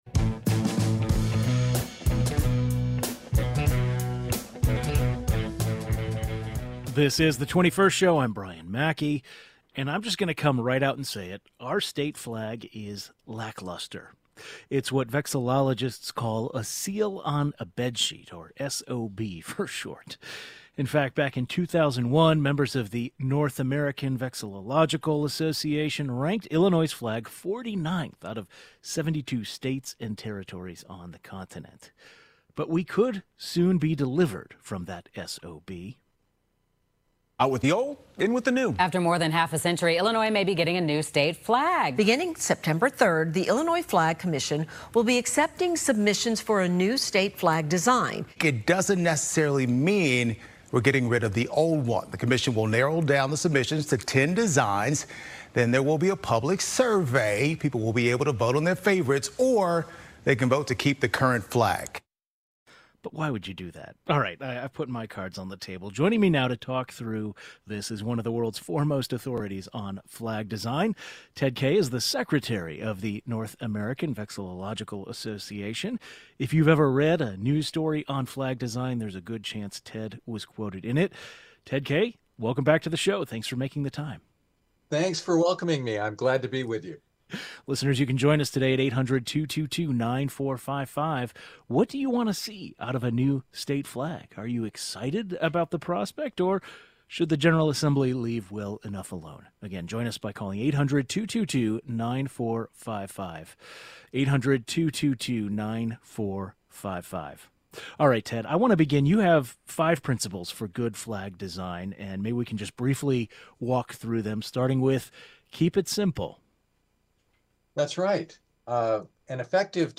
Illinois is accepting submissions for a new design for the state flag. Today, the state lawmaker who sponsored the law that created the Illinois Flag Commission joins the 21st as well as an expert on flag design.